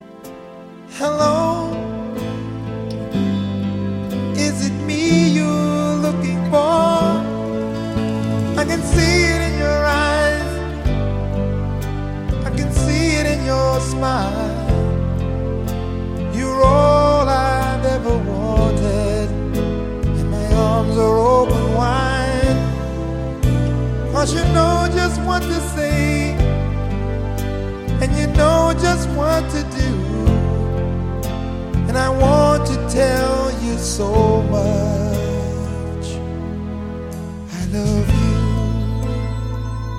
романтические , поп , ретро , легкий рок , 80-е